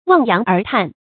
注音：ㄨㄤˋ ㄧㄤˊ ㄦˊ ㄊㄢˋ
望洋而嘆的讀法